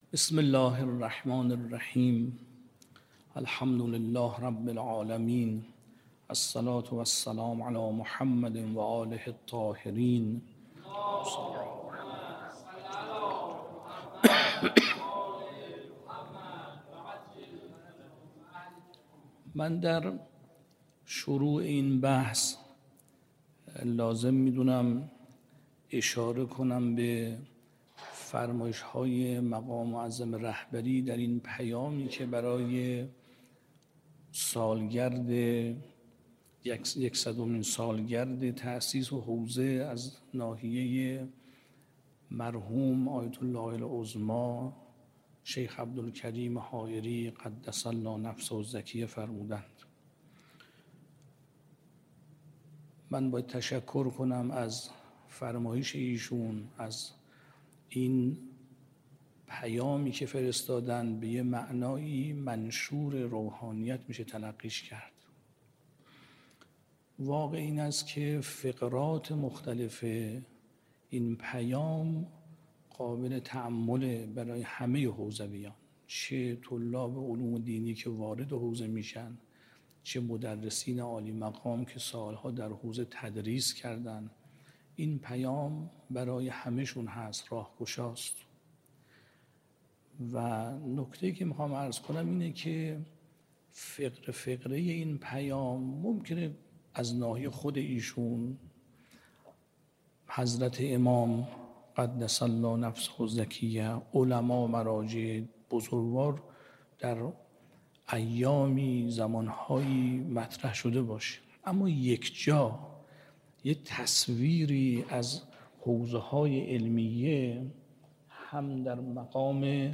به گزارش خبرگزاری حوزه، آیت‌الله صادق آملی لاریجانی در جلسه درس خارج خود با تحلیل دقیق و عمیق پیام رهبر معظم انقلاب اسلامی به مناسبت صدمین سال بازتأسیس حوزه علمیه قم، به تبیین نقش بی‌بدیل حوزه‌های علمیه در شکل‌دهی به فقه تمدن‌ساز و تأثیرگذاری جهانی پرداخت.